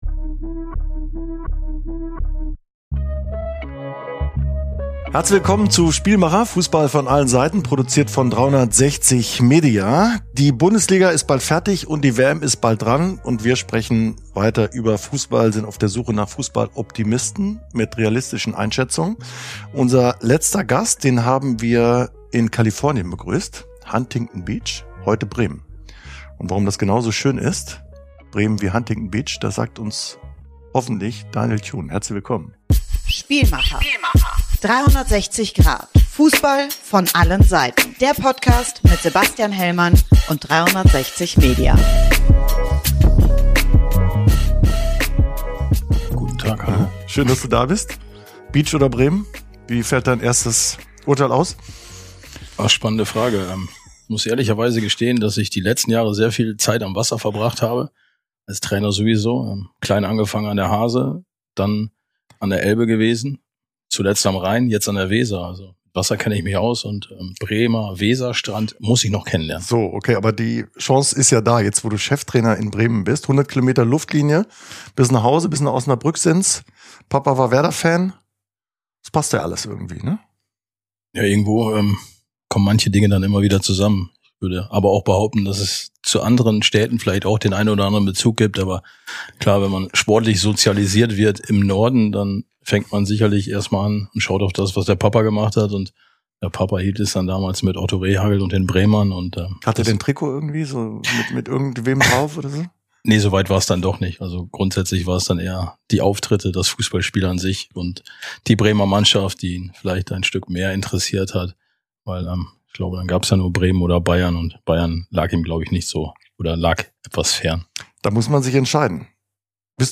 Im Gespräch mit Sebastian Hellmann spricht der neue Cheftrainer von Werder Bremen über seinen Weg durch den Profifußball, über Rückschläge und darüber, warum er sich bewusst dagegen entschieden hat, Abkürzungen zu nehmen.